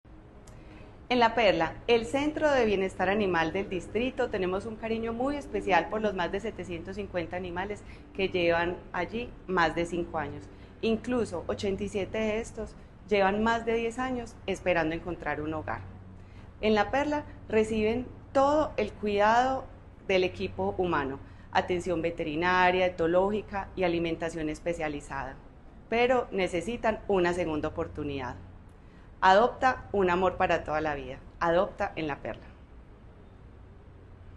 Declaraciones de la subsecretaria de Protección y Bienestar Animal, Elizabeth Coral En lo corrido de 2025, un total de 942 animales han encontrado un hogar a través del programa de adopciones responsables de la Alcaldía de Medellín.